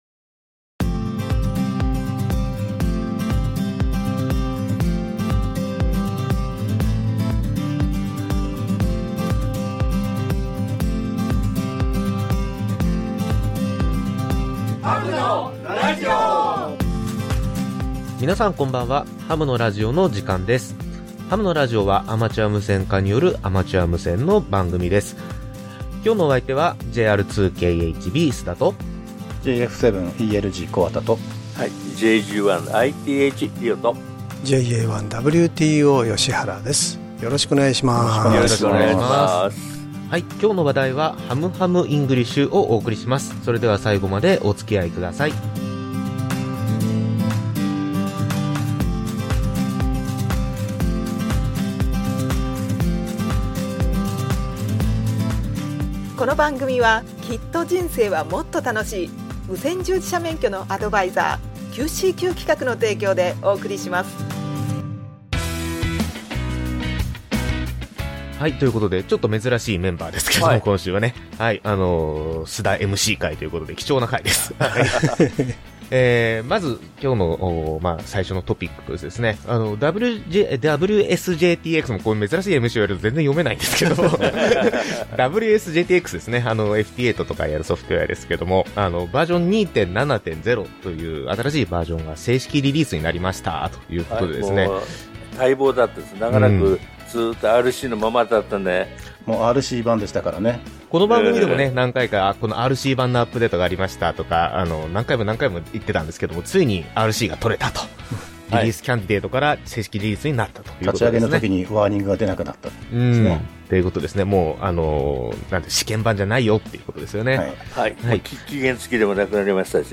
アマチュア無線家によるアマチュア無線のラジオ番組